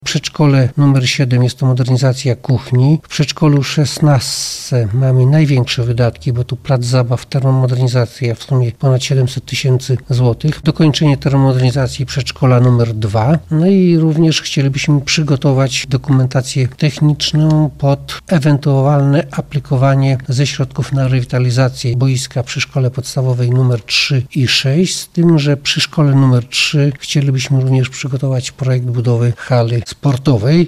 Tadeusza Kościuszki – mówi prezydent Puław, Janusz Grobel.